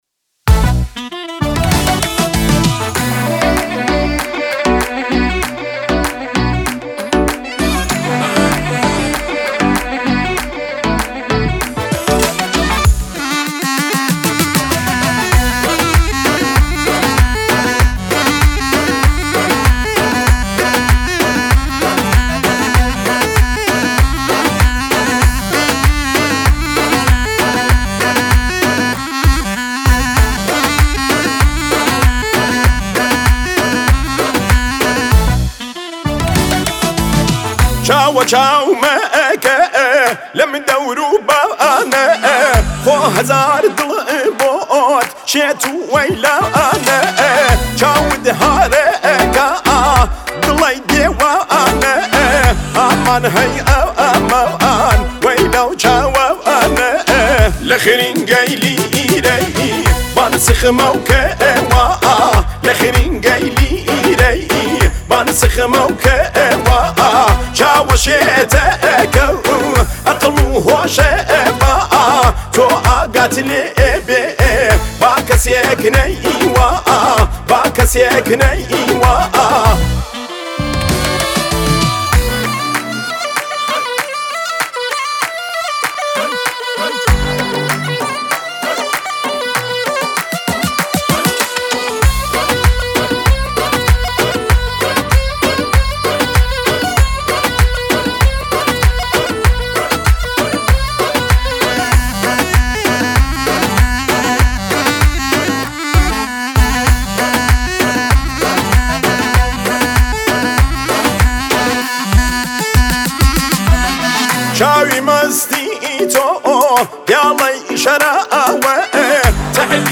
آهنگ کردی شاد آهنگ های پرطرفدار کردی